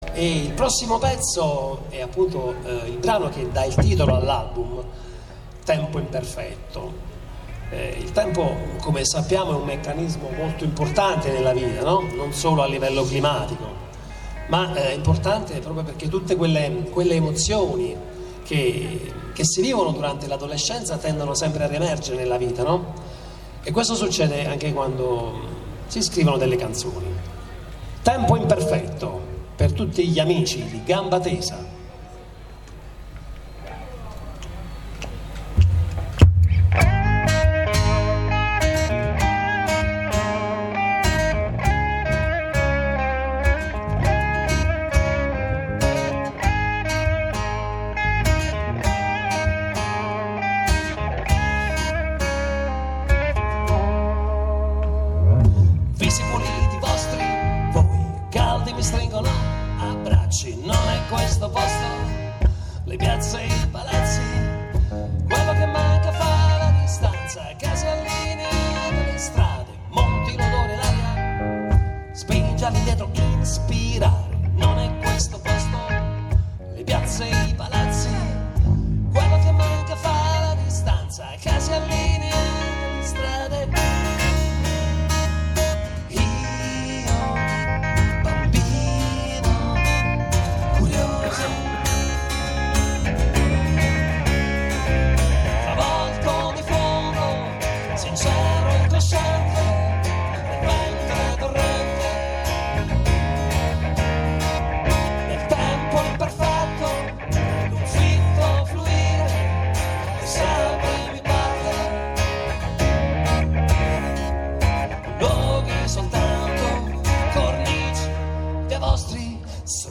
In Concerto A Gambatesa
Al Basso
Alla Chitarra